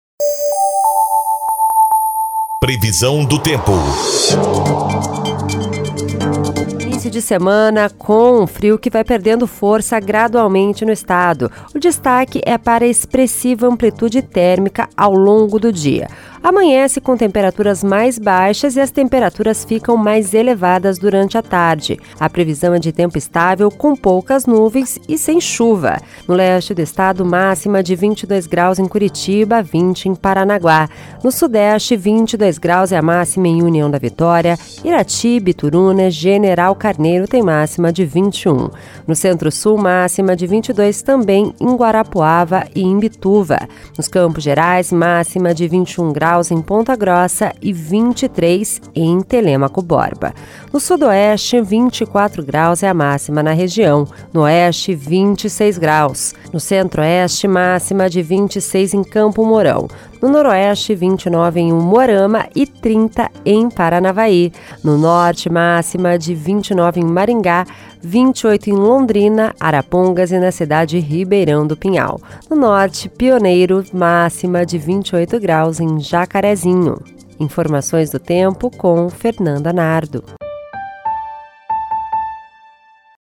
Previsão do tempo (01/08)